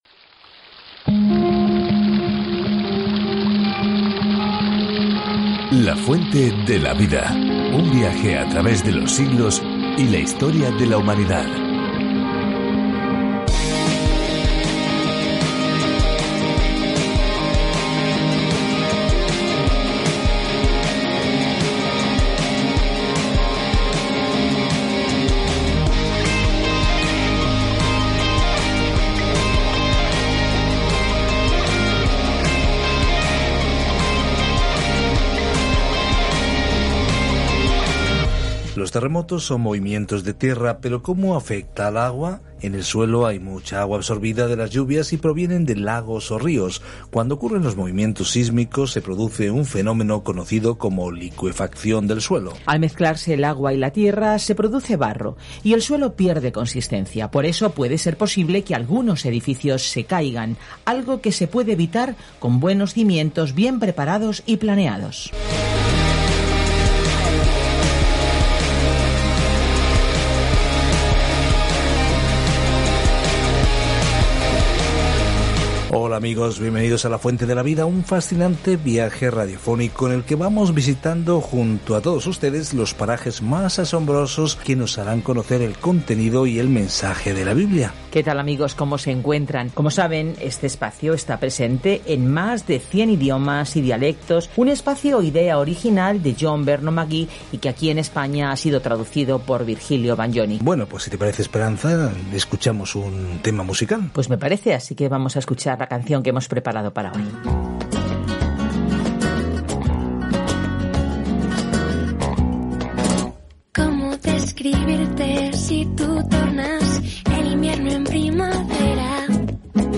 Viaja diariamente a través de Levítico mientras escuchas el estudio en audio y lees versículos seleccionados de la palabra de Dios.